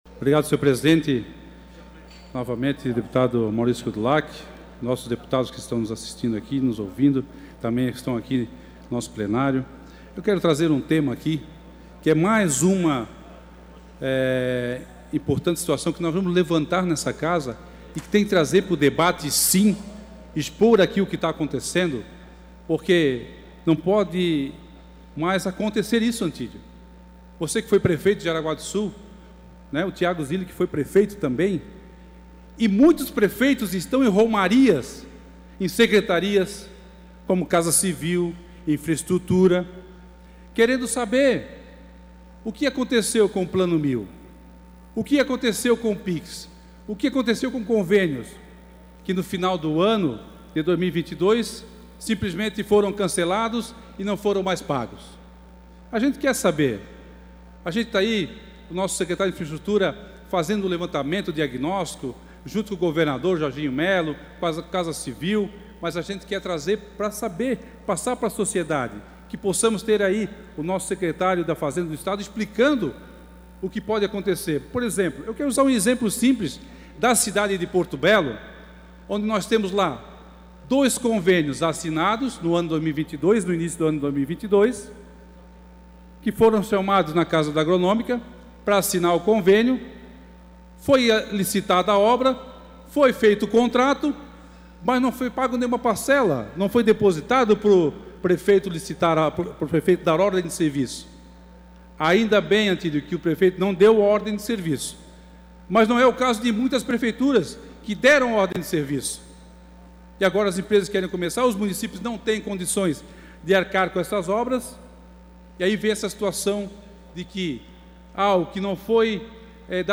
Pronunciamentos da sessão ordinária desta terça-feira (7)